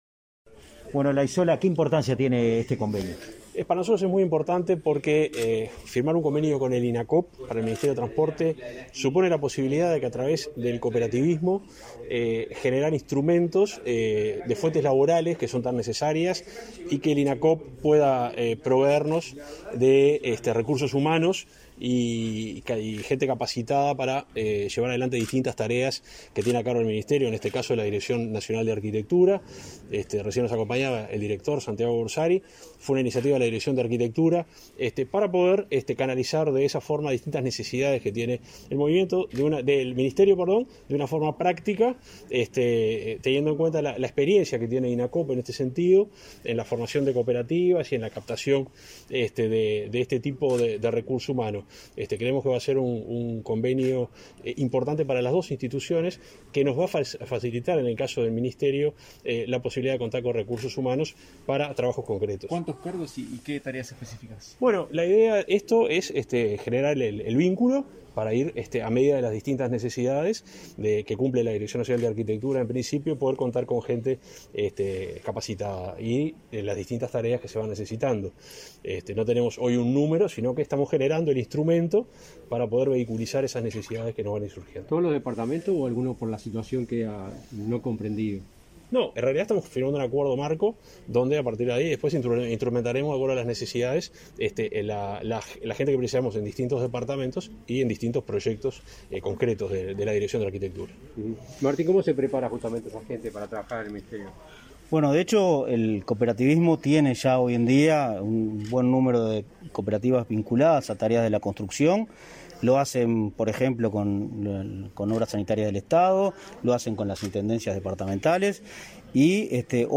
Declaraciones a la prensa del subsecretario del MTOP, Juan José Olaizola , y el presidente de Inacoop, Martín Fernández
Tras la firma del convenio entre el Ministerio de Transporte y Obras Públicas (MTOP) y el Instituto Nacional del Cooperativismo (Inacoop), este 17 de febrero, el subsecretario del MTOP, Juan José Olaizola , y el presidente de Inacoop, Martín Fernández, efectuaron declaraciones a la prensa.